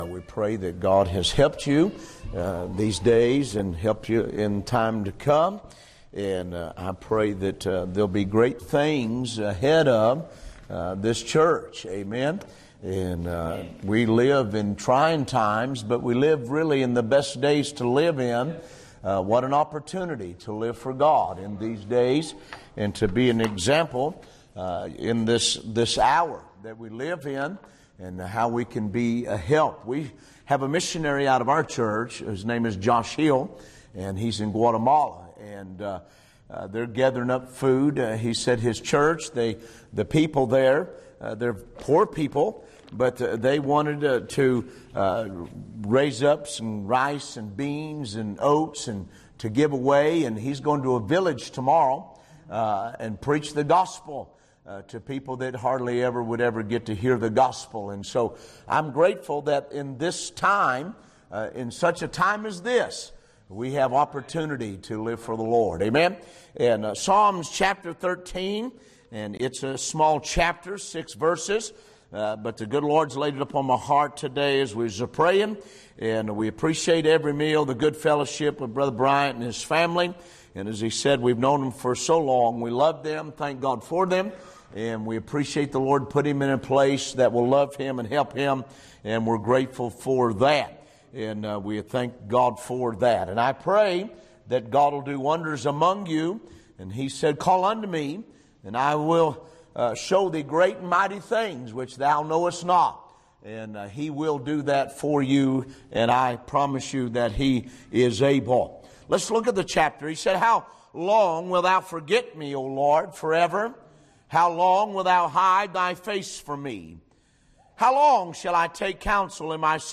Type Sermon or written equivalent